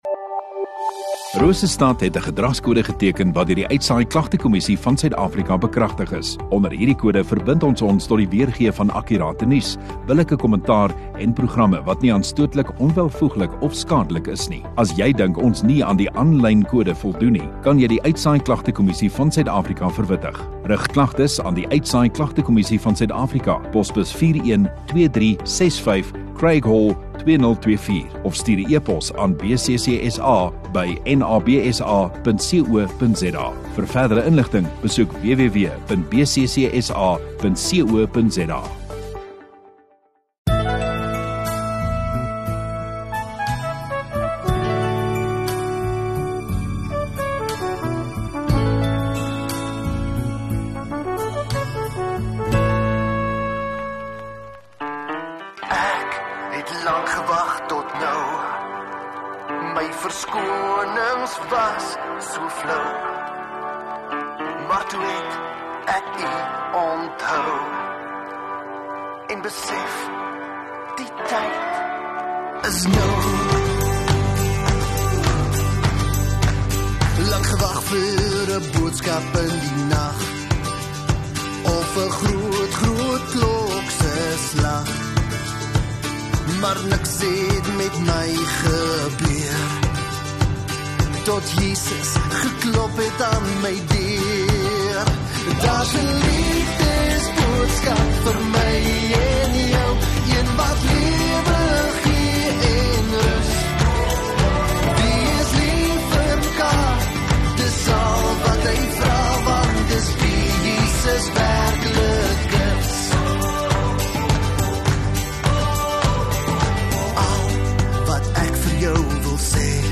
3 Aug Saterdag Oggenddiens